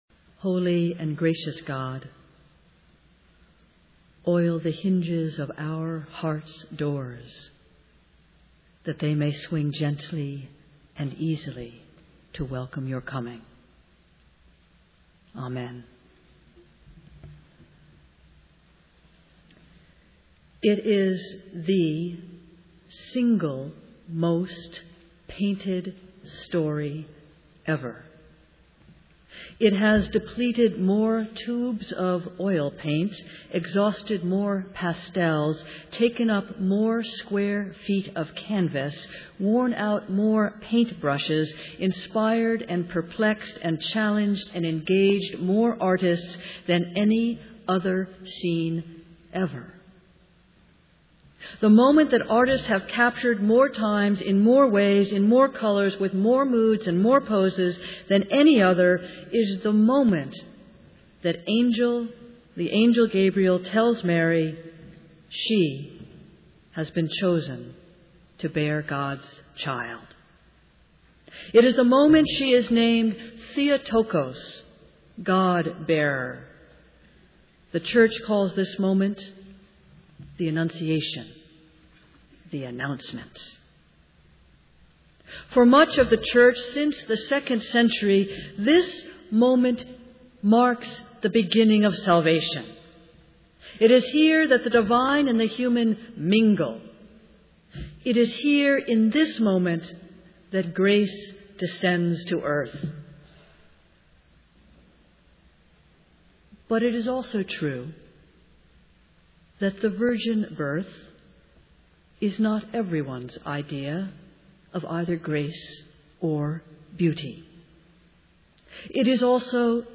Festival Worship